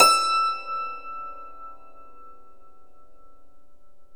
Index of /90_sSampleCDs/E-MU Formula 4000 Series Vol. 4 – Earth Tones/Default Folder/Hammer Dulcimer
DLCMR E4-R.wav